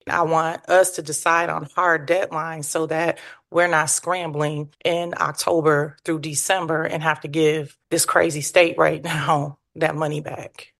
Commissioner Monteze Morales says she also has concerns.